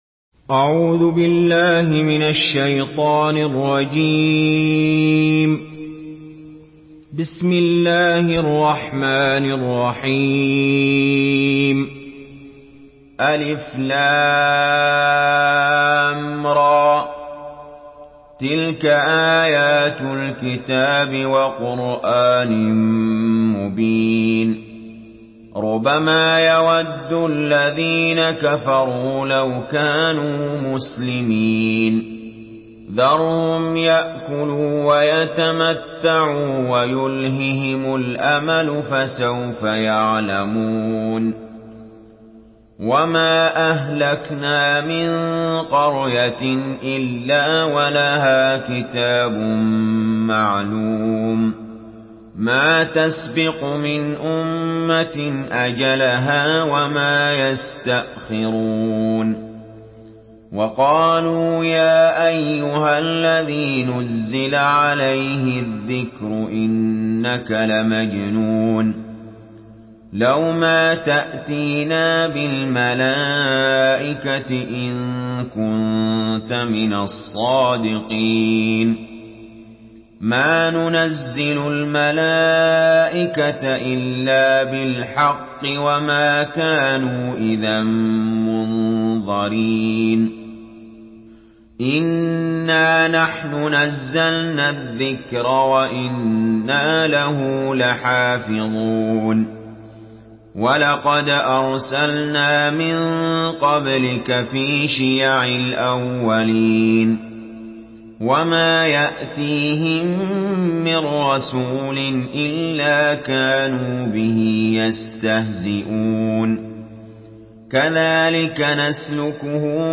Surah আল-হিজর MP3 by Ali Jaber in Hafs An Asim narration.
Murattal Hafs An Asim